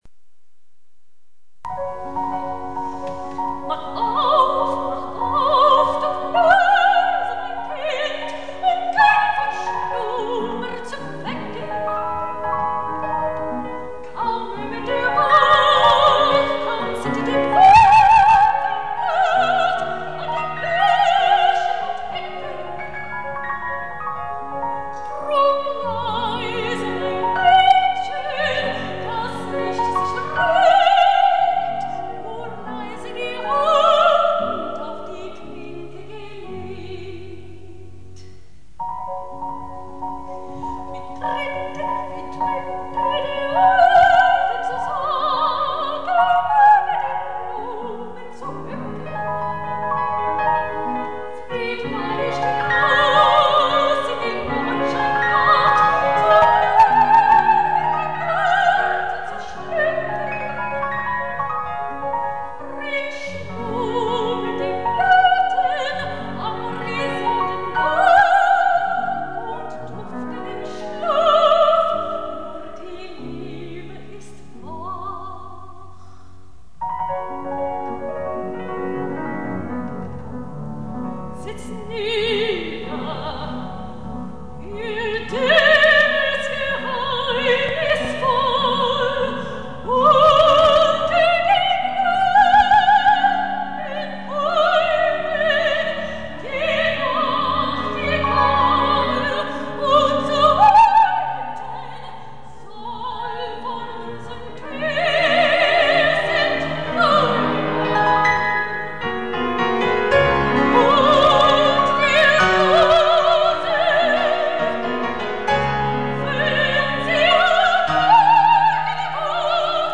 RECITAL  PRESTIGO
au piano